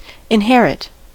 inherit: Wikimedia Commons US English Pronunciations
En-us-inherit.WAV